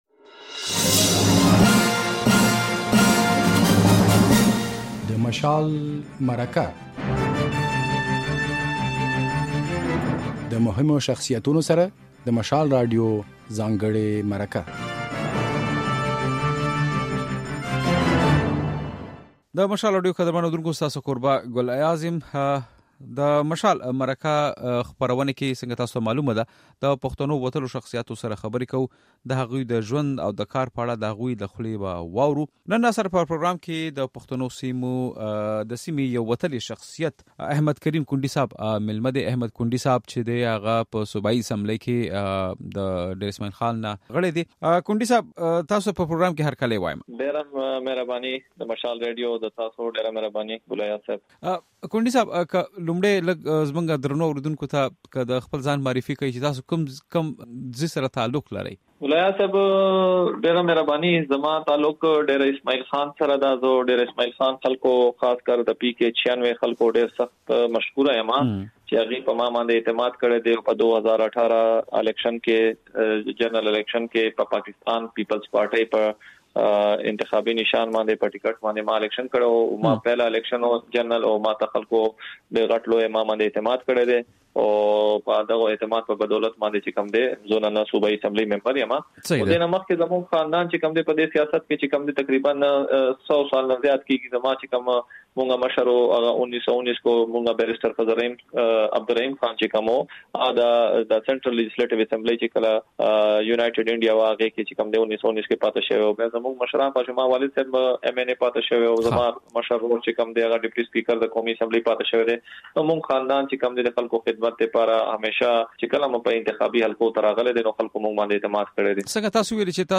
د مشال مرکه کې مو په خيبر پښتونخوا اسمبلۍ کې د پيپلز ګوند غړی احمد کريم کنډي مېلمه دی.